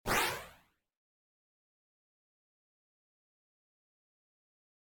flame2.ogg